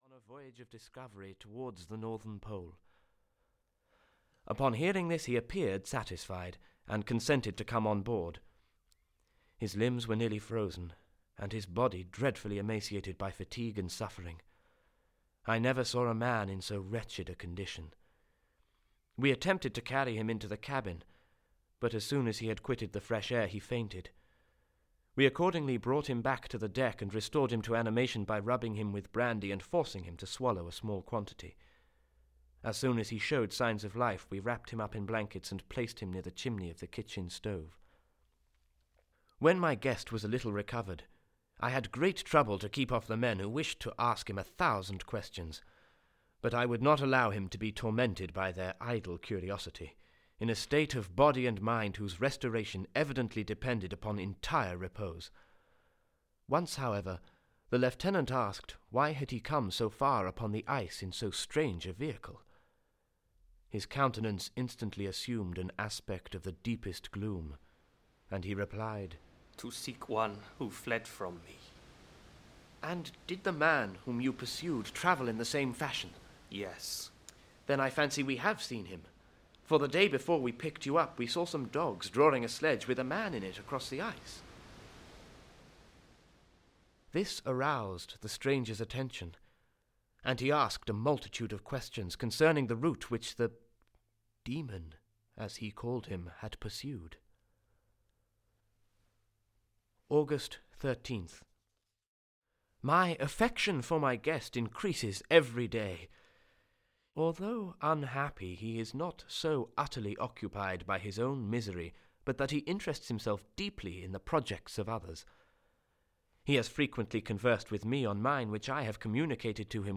Audio kniha
Ukázka z knihy
Her ideas, and her dramatic but poignant story, is brought to life in this sound dramatisation.